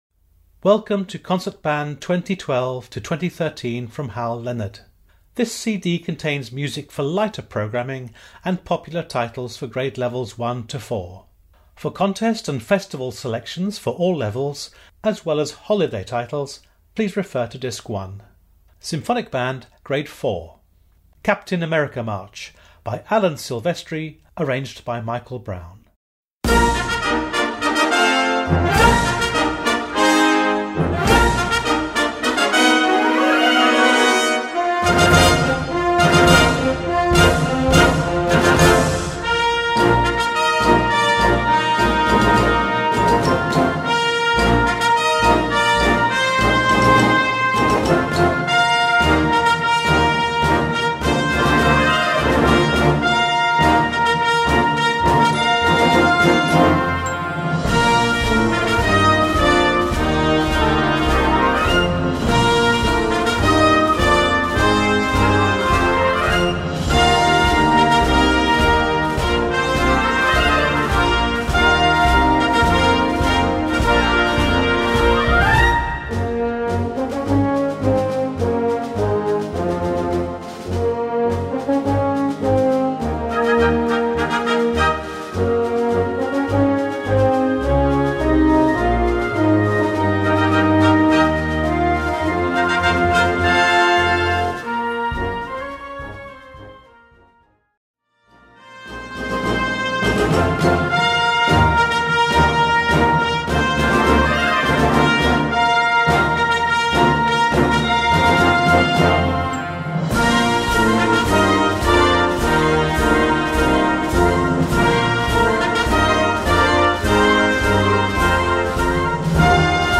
Gattung: Symphonic Band
Besetzung: Blasorchester
here is an exciting and uplifting march for mature groups.